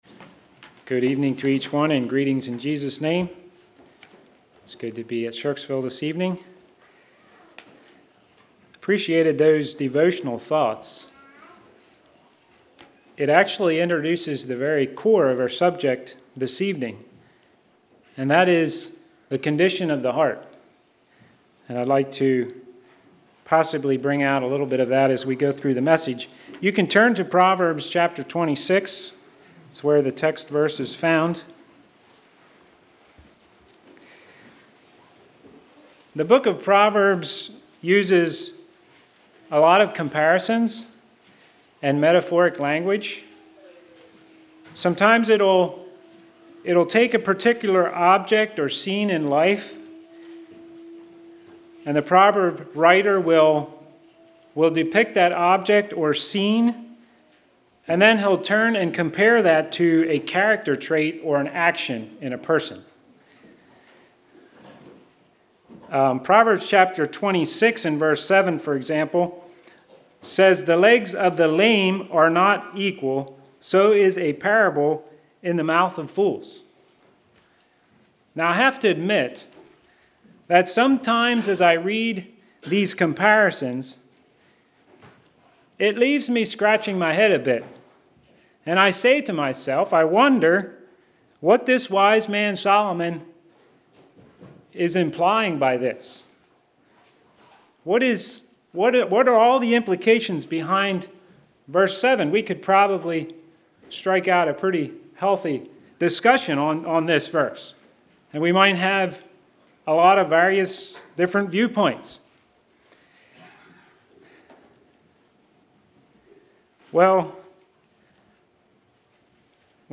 2017 Sermon ID